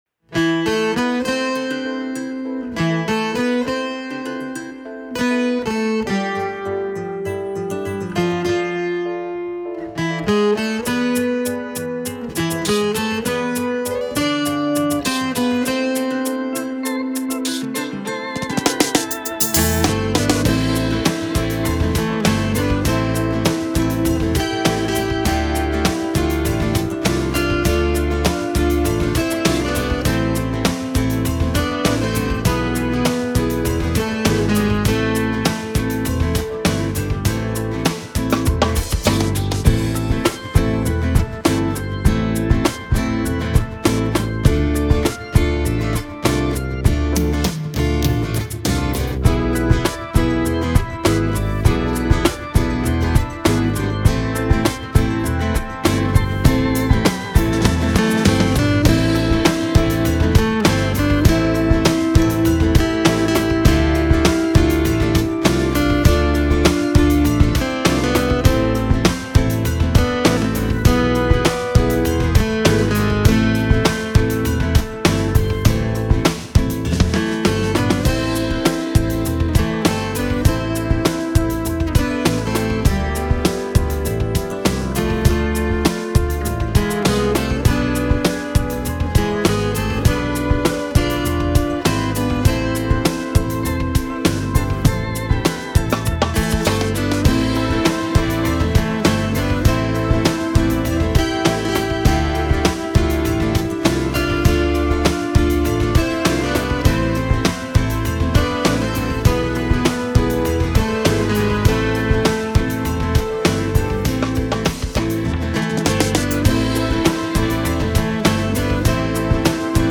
acoustic
epic
guitar
organ
drums
melancholic
film music
лирическая
мелодичная